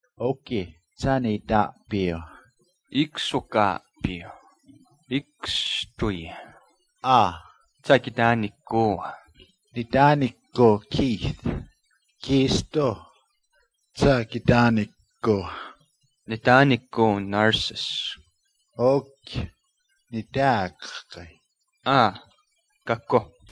Here’s a recording of a short conversation in a mystery language.